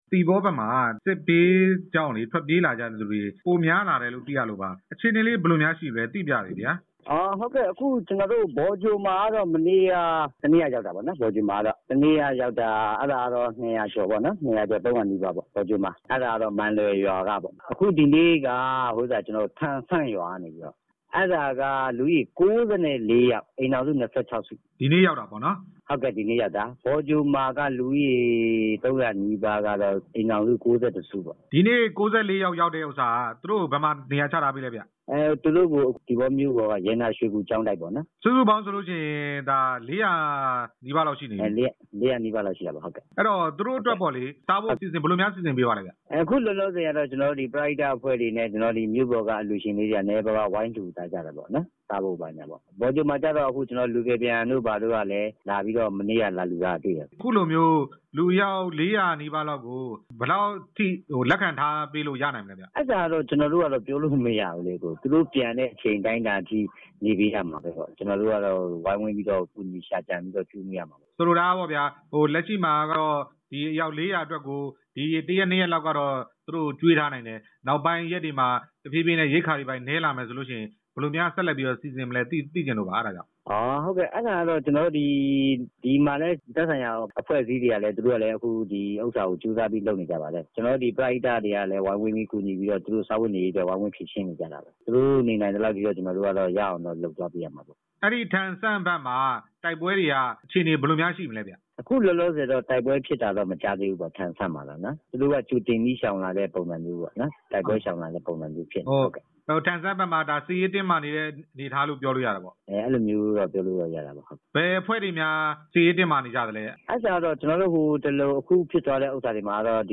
ရှမ်းမြောက်ပိုင်း ဒုက္ခသည်တွေ အကြောင်း မေးမြန်းချက်